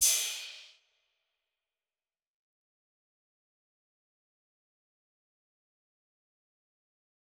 Crashes & Cymbals
DMV3_Crash 7.wav